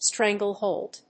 音節strángle・hòld 発音記号・読み方
/ˈstræŋgʌˌlhold(米国英語), ˈstræŋgʌˌlhəʊld(英国英語)/